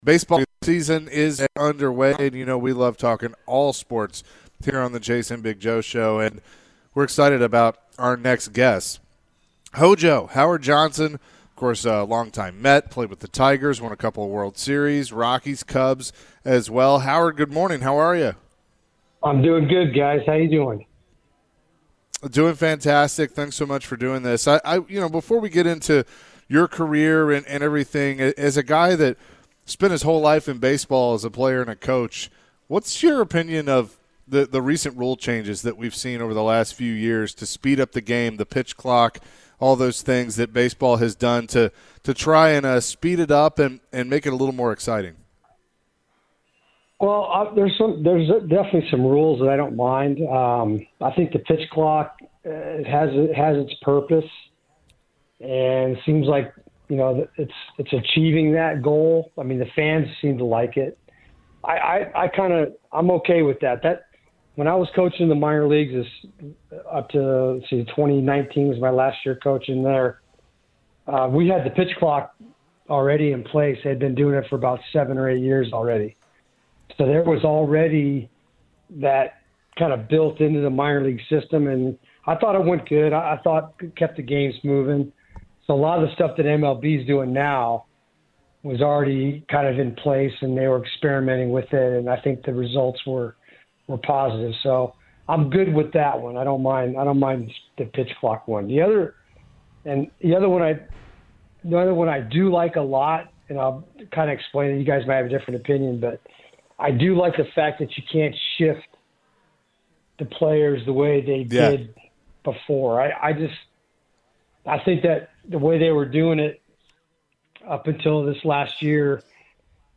Two-time World Series champion and New York Mets Hall of Famer Howard Johnson joins the show. Johnson talks about the new rule changes to the MLB and says the pitch clock was a great addition. He gets into the 1986 New York Mets and how special that team was.